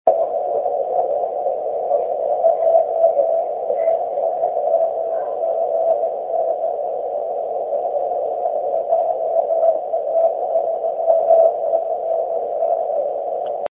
仕方なく携帯電話の動画機能でスピーカ出力を録音したのがこちらですが、うまく再生できるでしょうか…ワタクシの環境では絵しか出ません(;_;) (どなたか誰でも聞けるような形式に変換していただけませんか？(^^;; )追記部分にありますので、どうぞ。
(6/ 1追記：ある親切な方が、変換したファイルを早速メールして下さいました。ありがとうございました！　上述の通りスピーカから間接的に録音したのでちょっと聞きにくいかもですが、